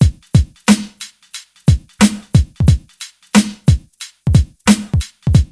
HM90BEAT1 -R.wav